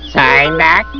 psyduck.wav